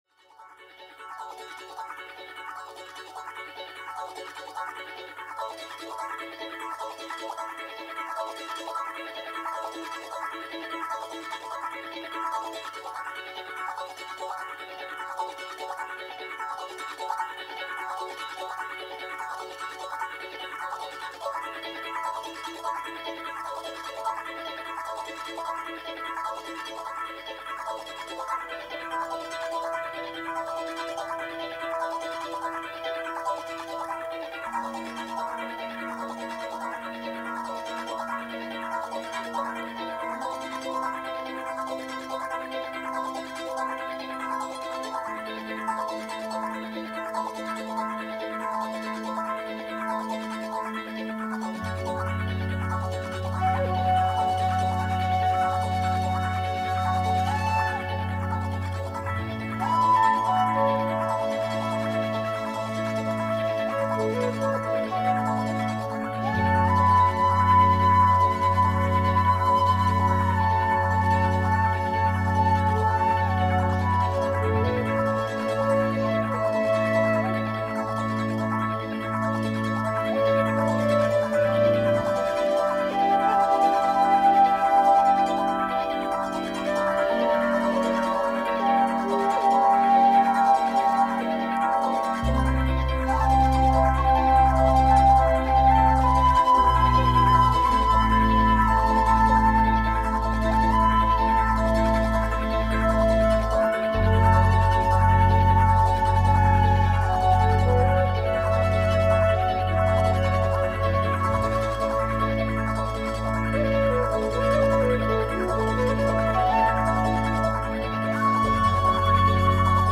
Argentinian folk jazz rarity !